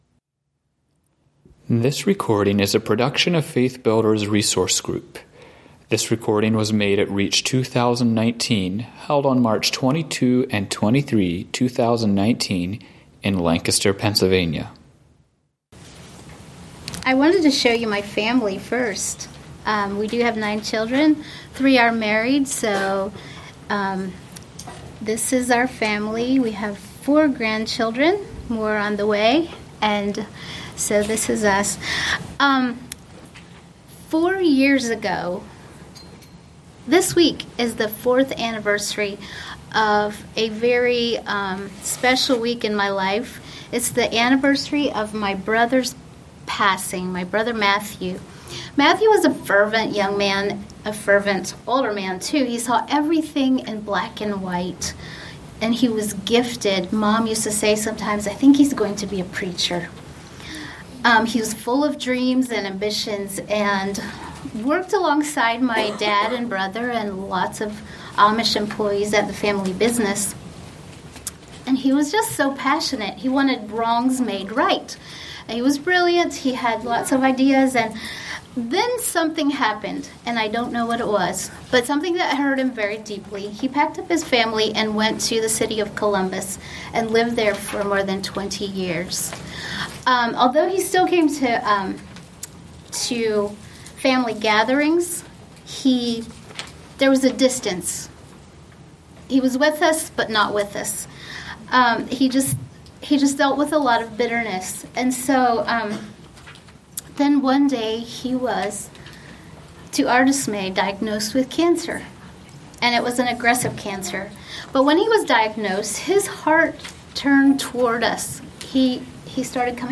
Home » Lectures » Glassenheit: Coming to Terms With What Is